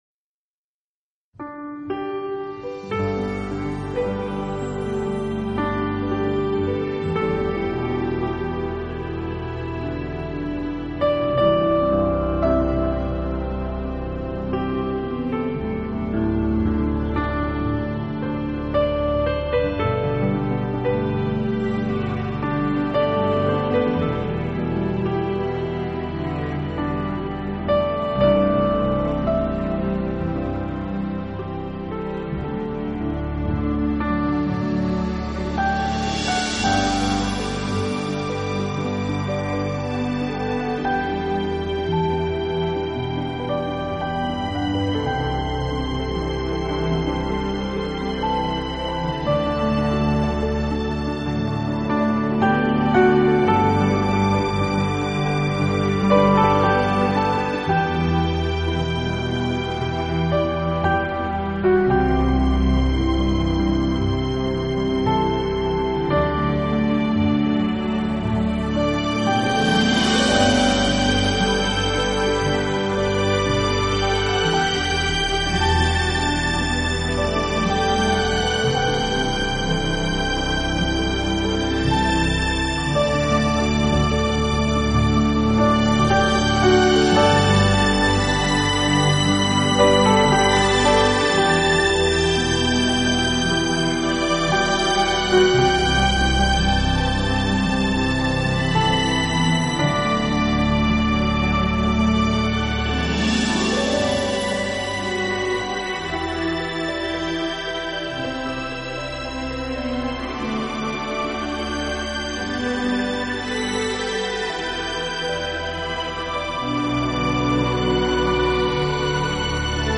【新世纪钢琴】